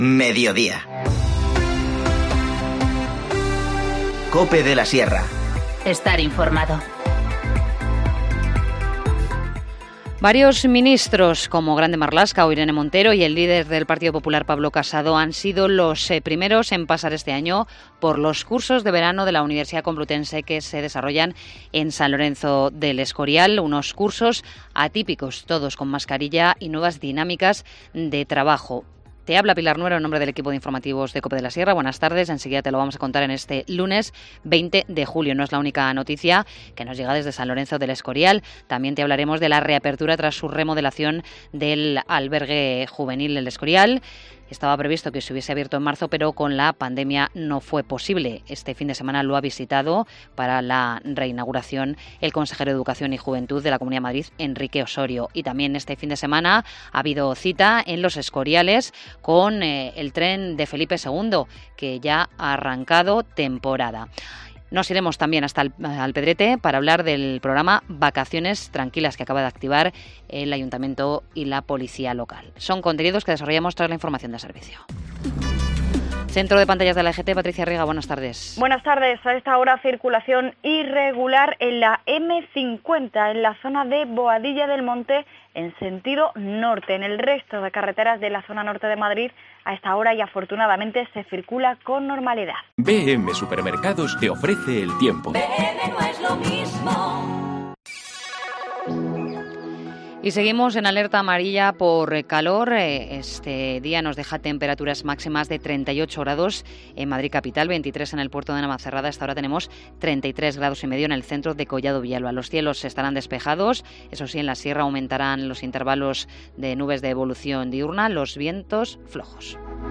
Informativo Mediodía 20 julio